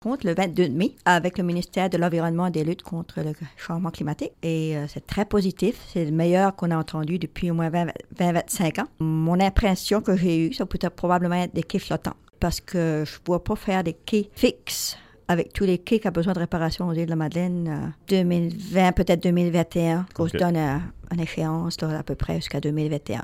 La mairesse de Grosse-Île Rose-Elmonde Clark, qui siège au comité directeur, souligne que la construction d’un nouveau quai flottant, qui permettra les débarquements sécuritaires sur l’Île, pourrait s’étendre sur deux ans avant d’être complétée :